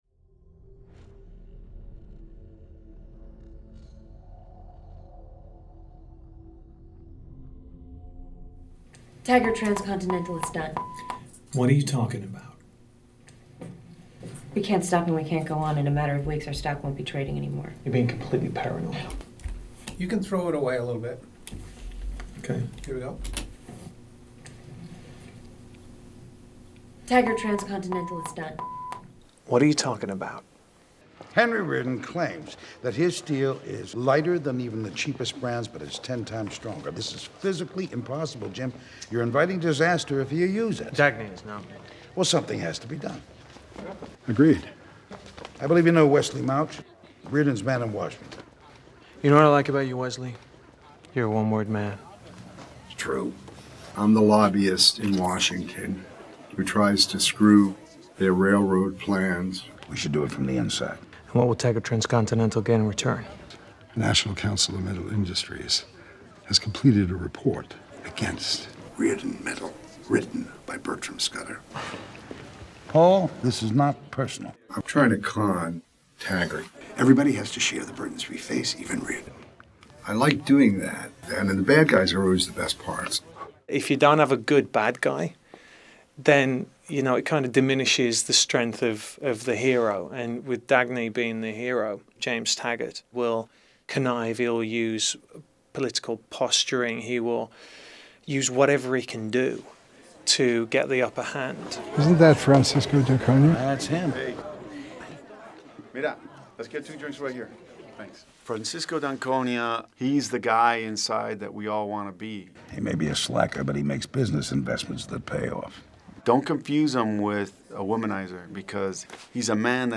***SPOILER ALERT*** This video contains portions of a scene and actors discussing the actions of their characters.